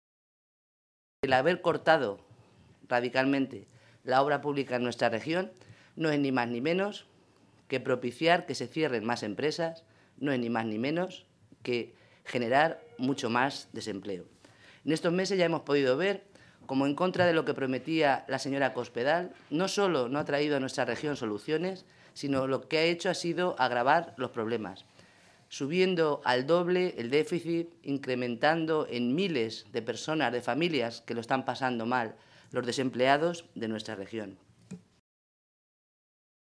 Rosa Melchor, diputada regional del PSOE de Castilla-La Mancha
Cortes de audio de la rueda de prensa